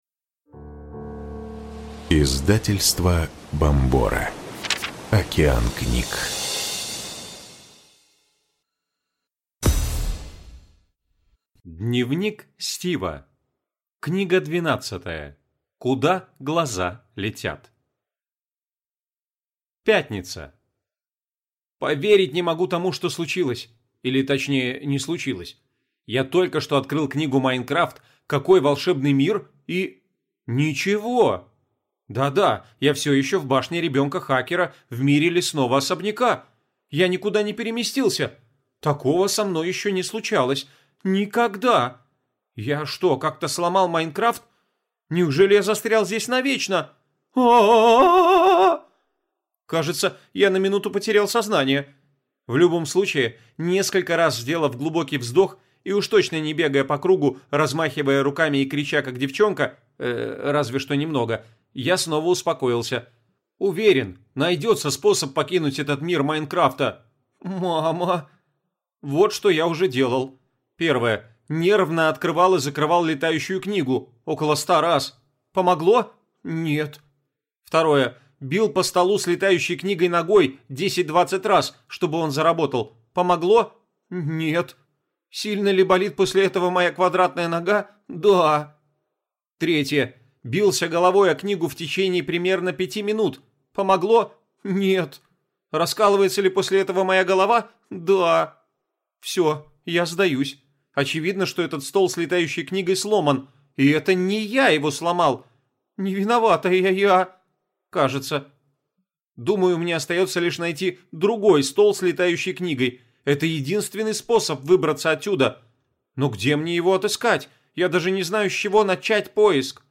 Аудиокнига Куда глаза летят | Библиотека аудиокниг